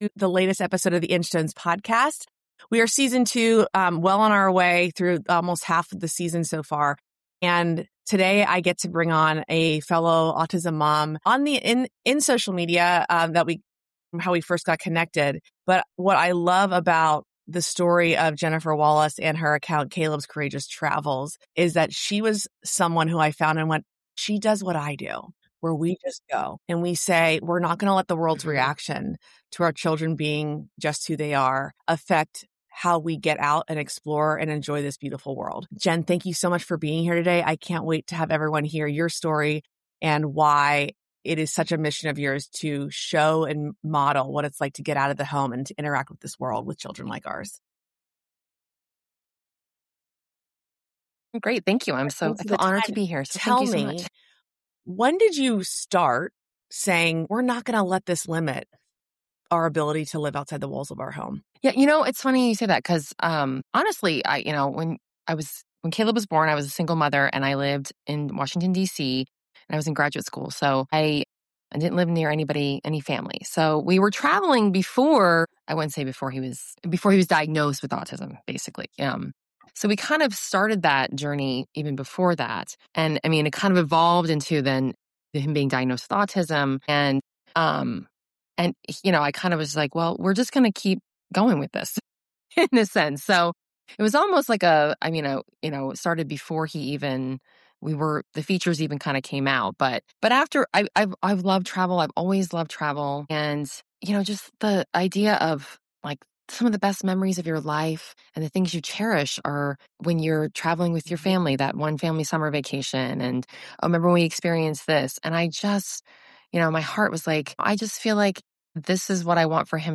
They discuss autism travel tips, sensory considerations, preparation strategies, and how community support can make travel more accessible. This conversation is grounded in real experience and offers practical guidance for families raising children with autism, including those with nonspeaking autism.